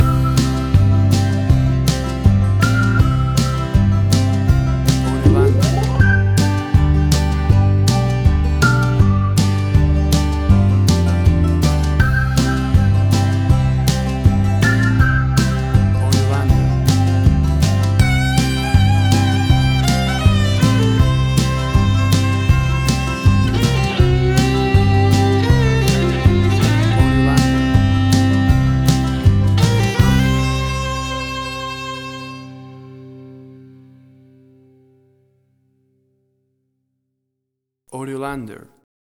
WAV Sample Rate: 16-Bit stereo, 44.1 kHz
Tempo (BPM): 80